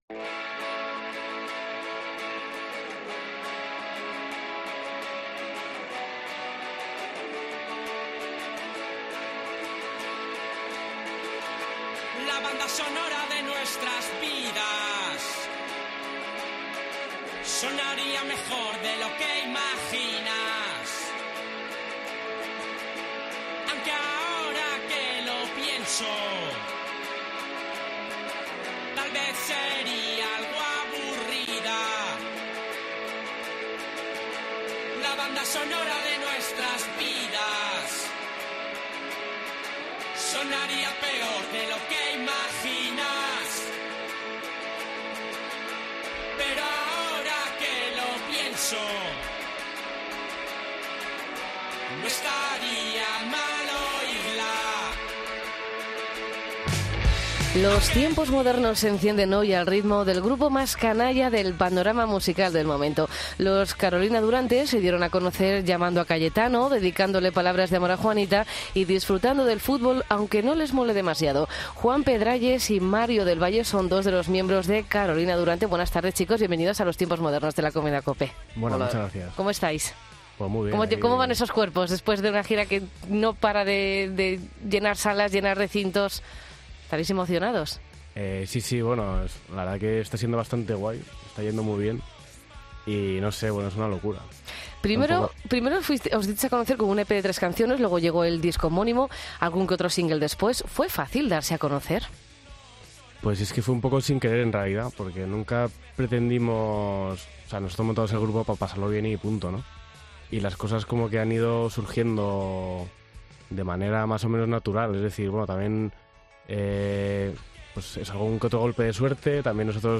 Entrevista a Carolina Durante en los Tiempos Modernos